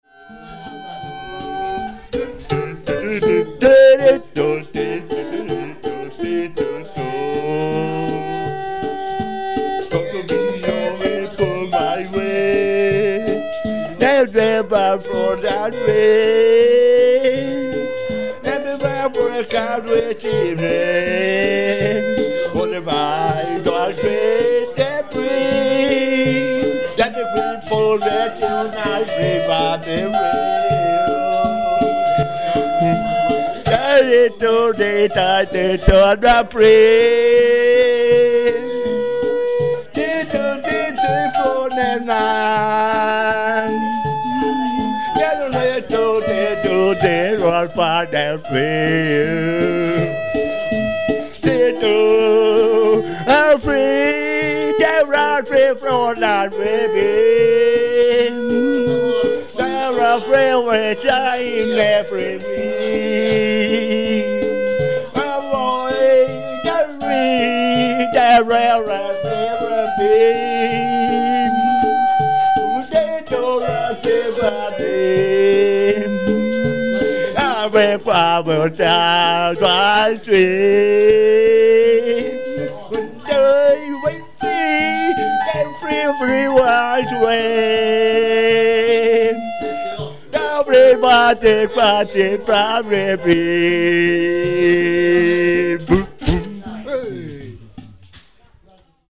outsider musician